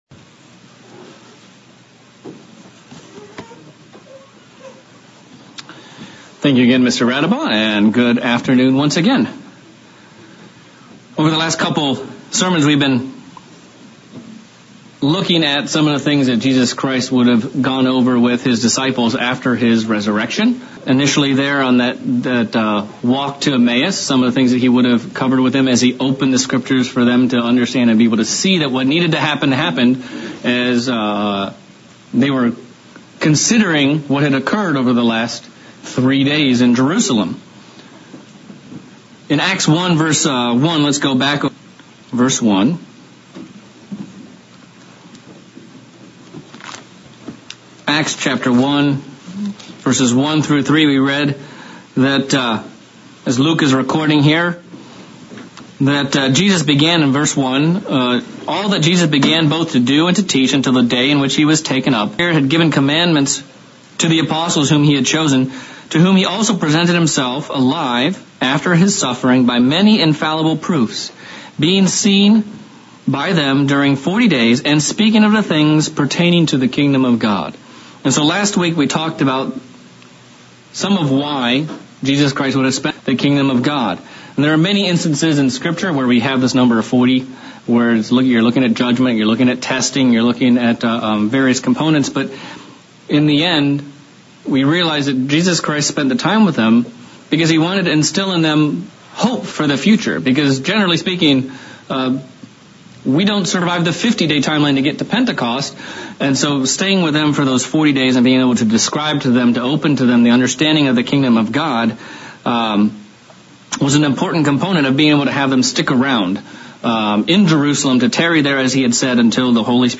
Sermon looking at the Kingdom parables of Matt 13 and how much we should value and treasure the Kingdom of God and give everything we have for it.